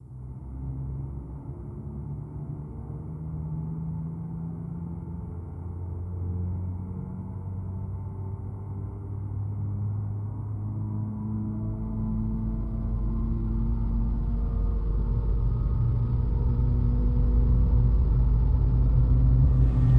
CFM56 startup sounds
A320_cockpit_starter.wav